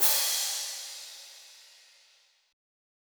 Percs & Hits (17).wav